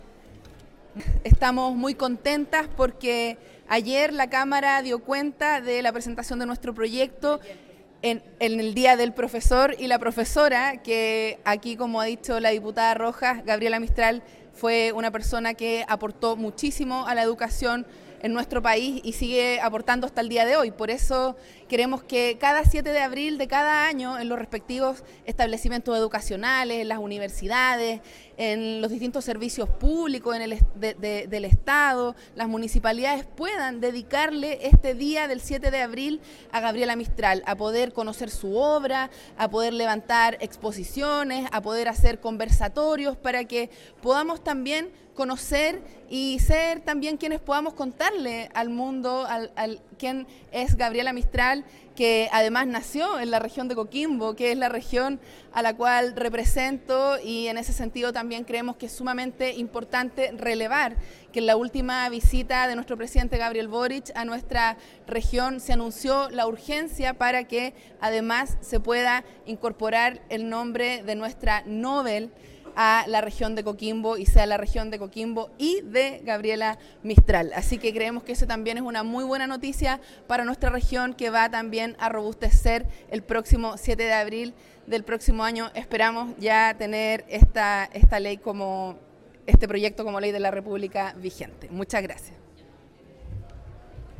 La diputada Tello explicó que
DIPUTADA-TELLO.mp3